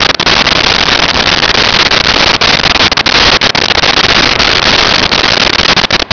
Sfx Amb Surf Loop
sfx_amb_surf_loop.wav